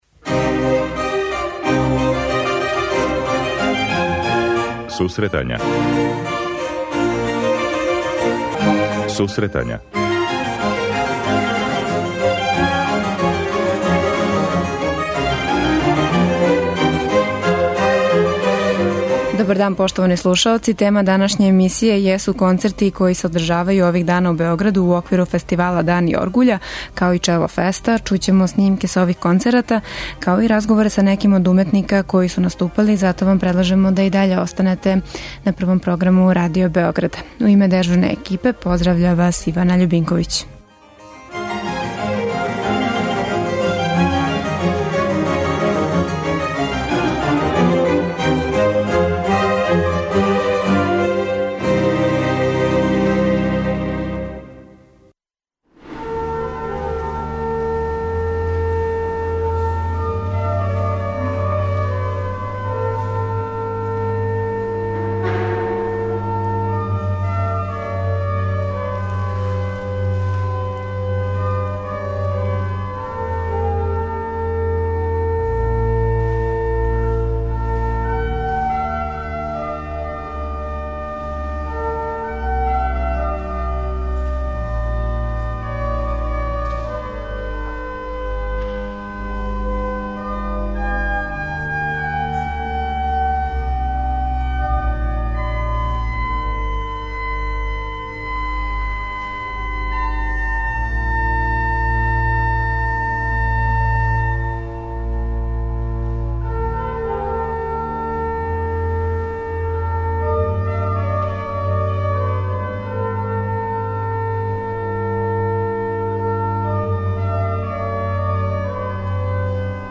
У емисији говоримо о фестивалу Дани оргуља као и о Чело фесту, слушаћемо разговоре са уметницима као и снимке са концерата.